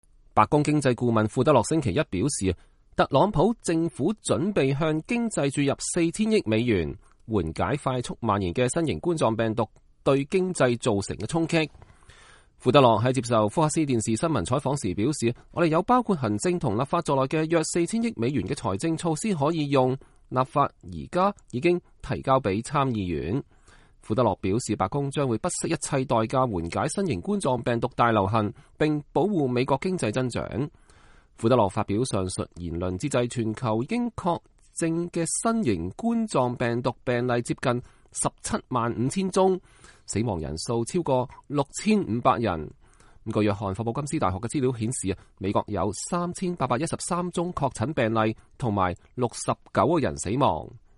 白宮首席經濟顧問庫德洛2月25日對記者說，我相信美國擁有世界上最好的公共衛生系統。